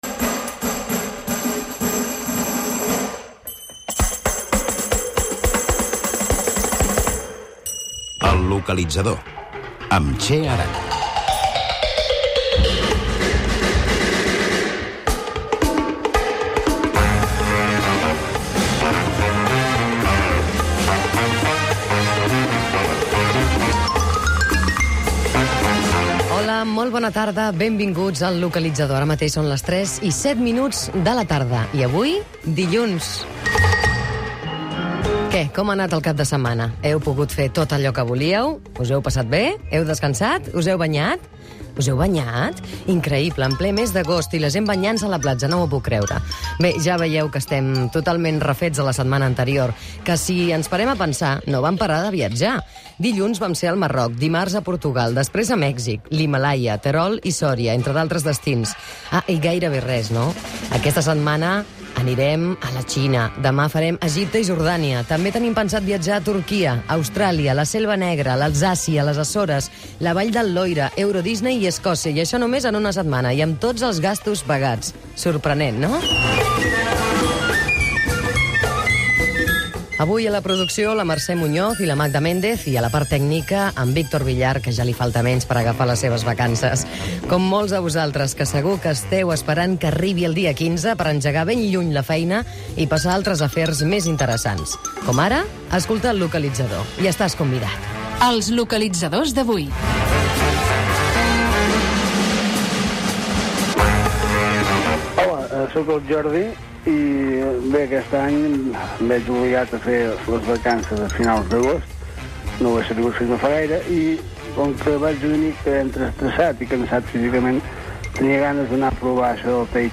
Indicatiu del programa, salutació, sumari de continguts de la setmana, equip, identificació del programa, pregunta d'un oïdor sobre el taitxí, lectura d'un correu electrònic demanat informació sobre un viatge a la Xina, identificació del programa, recomanacions per viatjar a la Xina i llocs d'interès Gènere radiofònic Divulgació